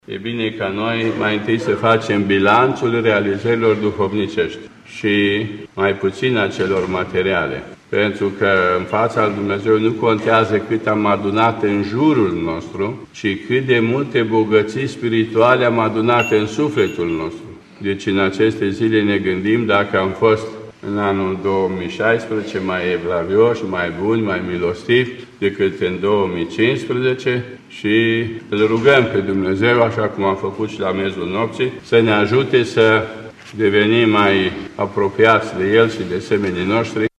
Credincioşii au participat în prima zi a anului la Liturghia care a marcat trei sărbători: Tăierea Împrejur a Domnului, Sfântul Vasile cel Mare şi intrarea în Noul An. La Catedrala Patriarhală din Bucureşti, slujba a fost oficiată de Patriarhul Daniel al Bisericii Ortodoxe Române: